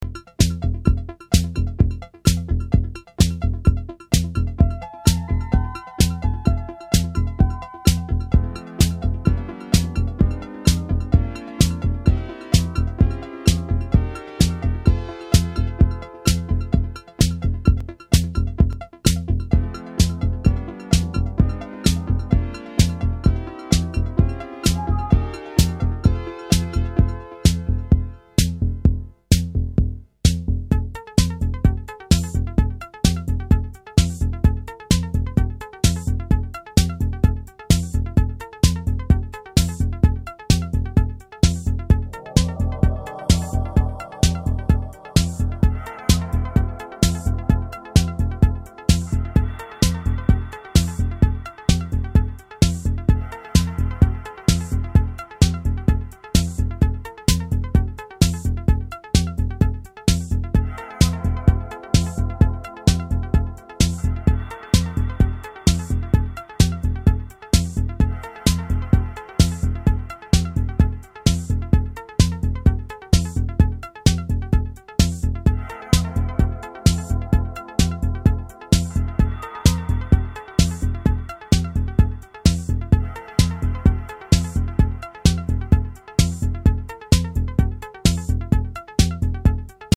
Electro disco recommended for Italo disco and cosmic!